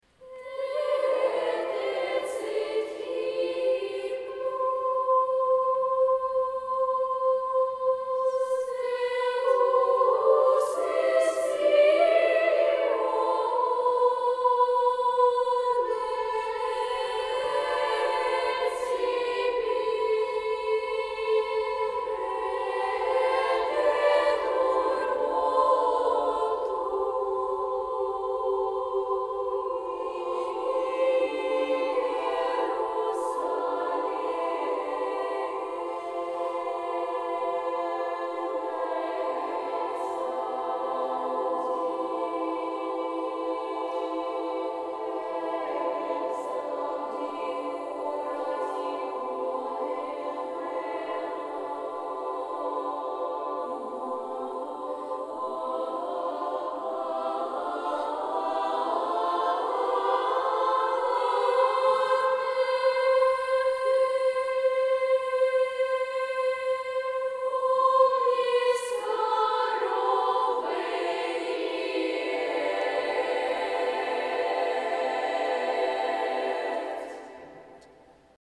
Детский хор
Попросили "вытащить верхние голоса" на концертной записи, послушайте, у кого найдётся досуг, что получилось.
Звук пойдёт под видео в интернет, как я понял.